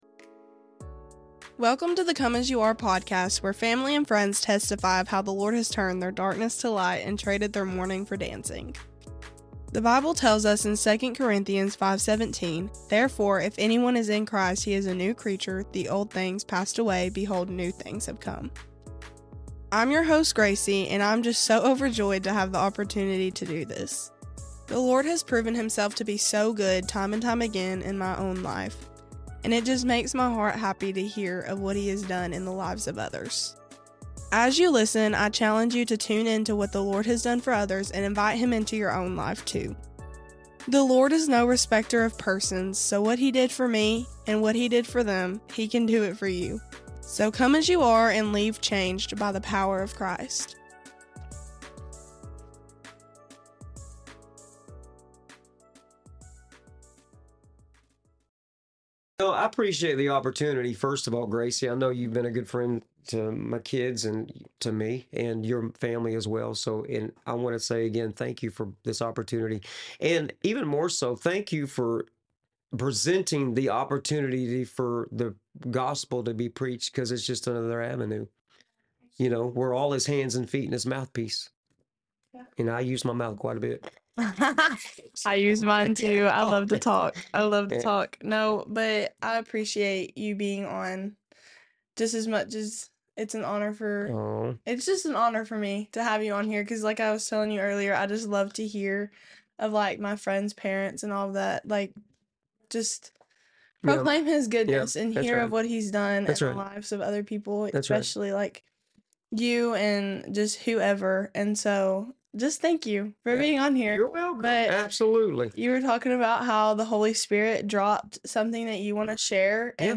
I pray that the Holy Spirit reveals Himself to you through this conversation.